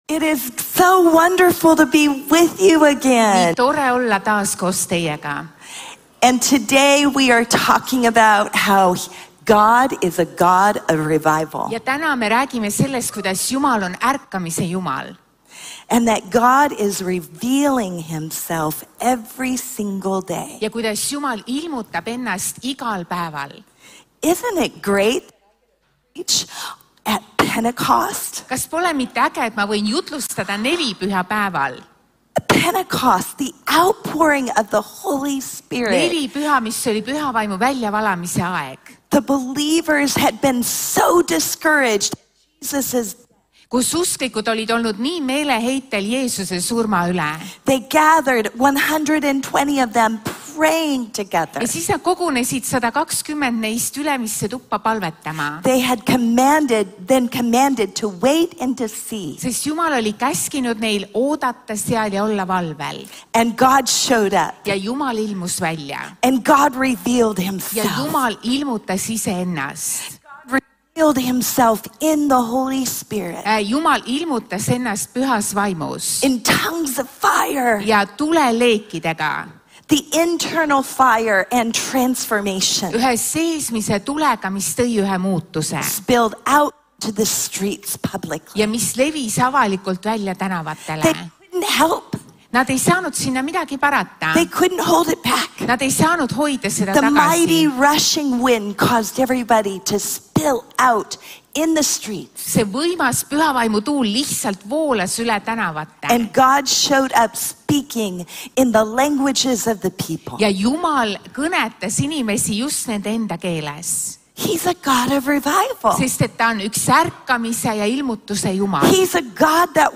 1257_jutlus.mp3